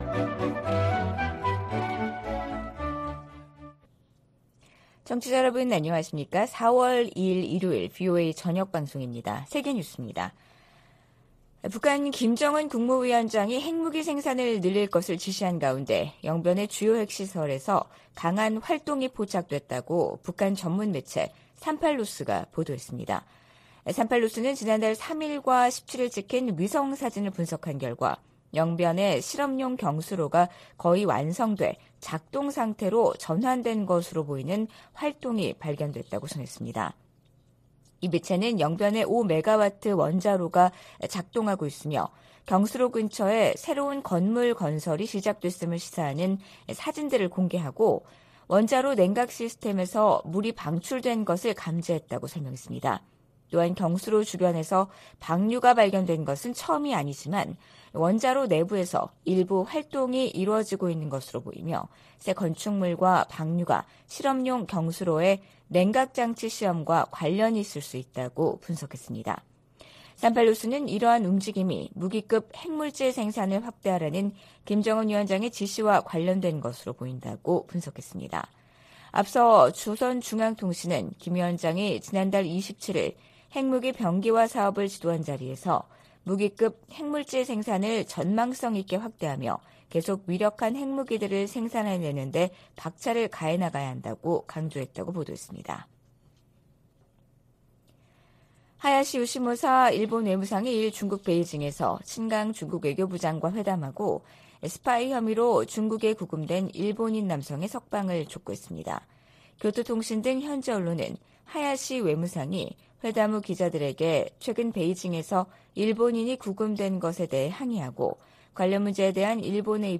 VOA 한국어 방송의 일요일 오후 프로그램 2부입니다.